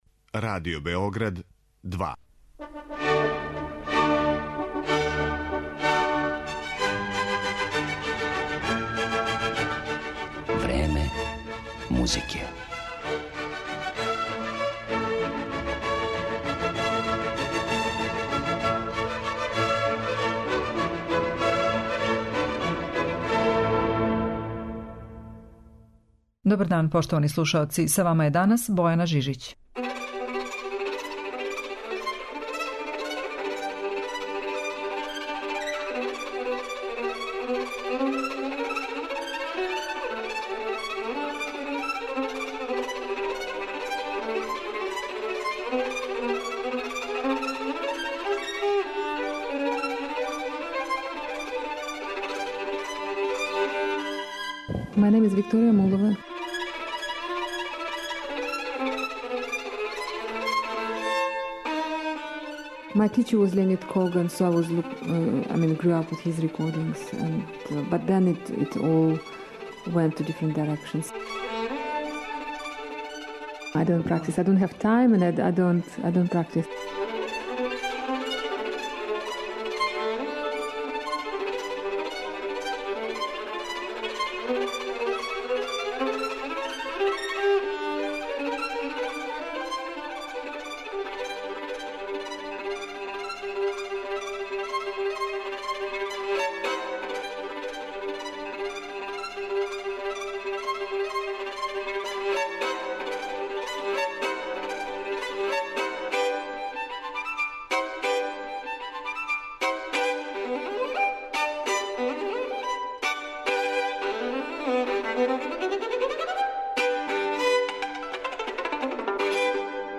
Од када је 1980. године победила на великом такмичењу Сибелијус, а две године касније освојила златну медаљу на чувеном конкурсу Чајковски, Викторија Мулова је стекла светску славу и постала један од најтраженијих солиста на виолини.
Данашње Време музике је посвећено овој славној руској уметници, коју ћемо представити и као солисту и као камерног музичара.